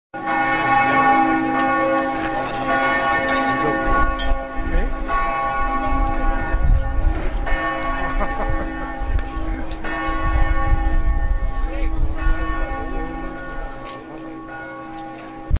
向かいに広がる王宮を回る
広場に出ると１２時の鐘が鳴る（上記の写真をクリックしてください）